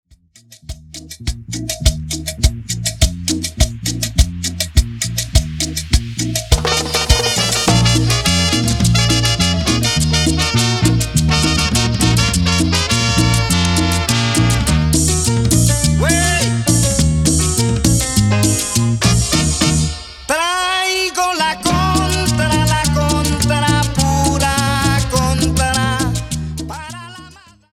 Extended Dirty Intro
Cumbia